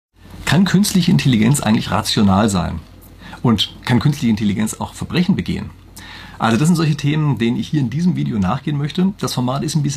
in einem Interview.